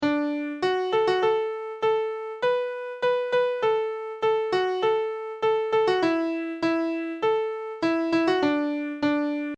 The tune is then repeated, but player don't sing, they hum and do the following:
clappinggame.mp3